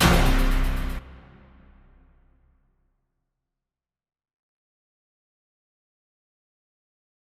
MDMV3 - Hit 19.wav